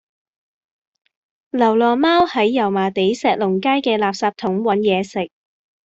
Голоса - Гонконгский 90